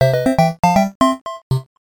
Ripped with Nitro Studio 2